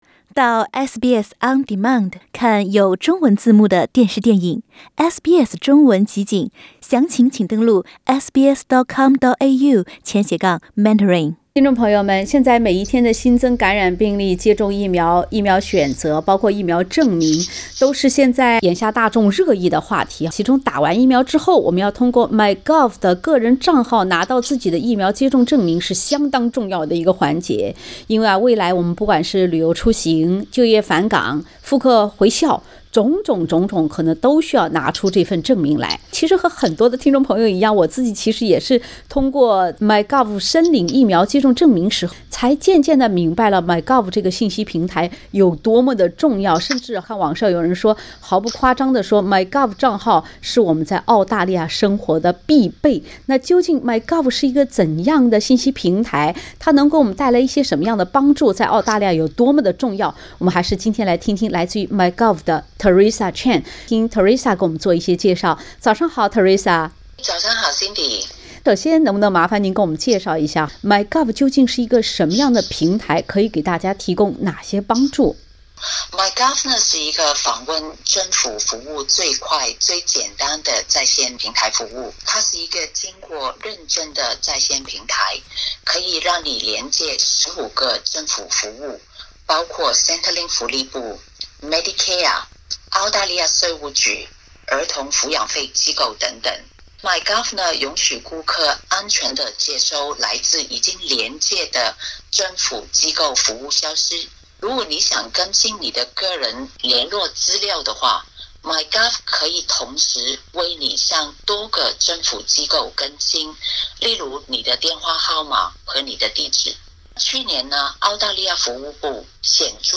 （點擊鞥封面圖片，收聽完整寀訪）